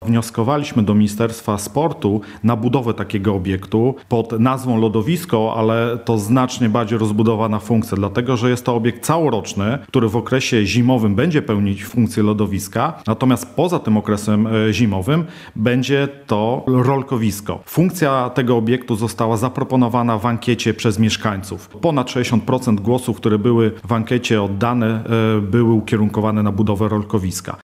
– Będzie to obiekt całoroczny – mówi w rozmowie z Radiem Lublin burmistrz Łęcznej Leszek Włodarski.